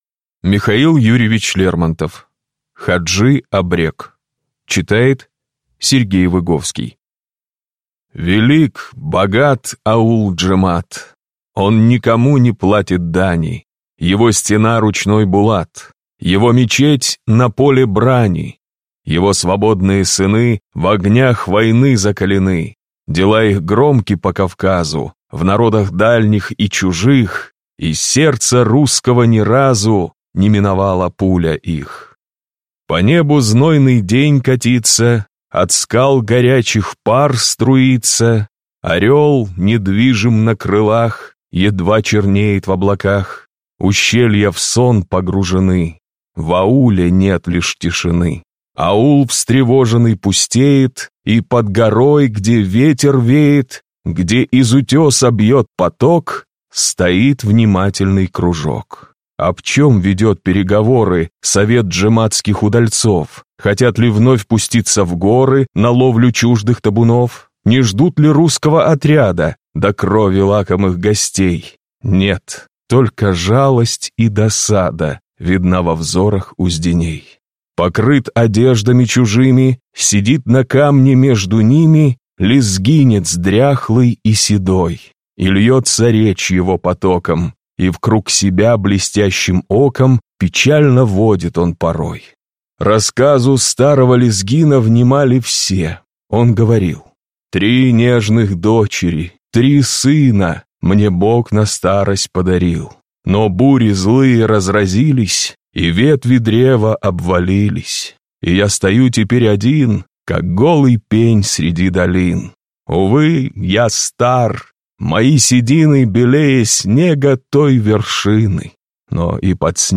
Аудиокнига Хаджи Абрек | Библиотека аудиокниг